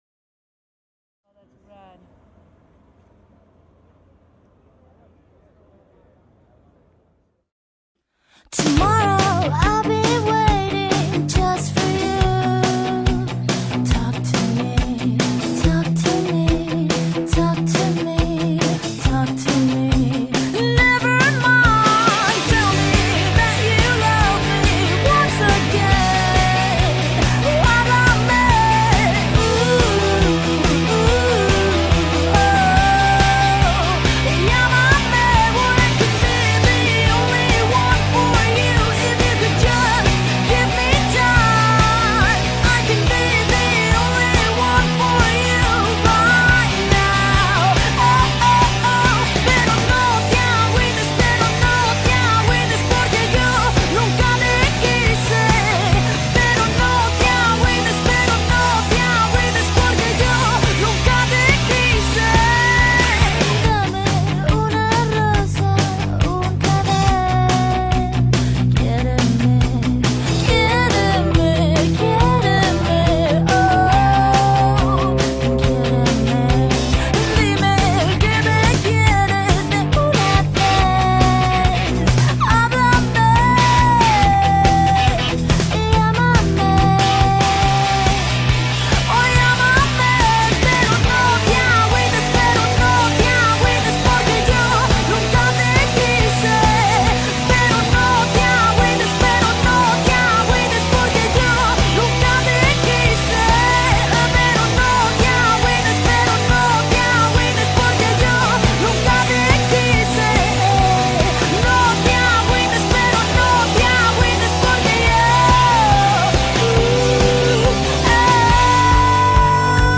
una banda de rock mexicana
Rock Alternativo